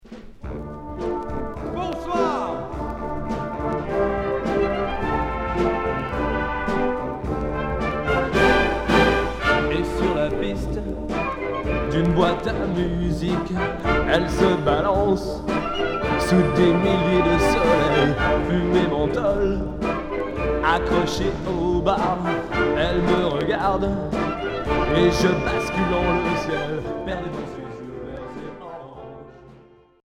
Medley
New wave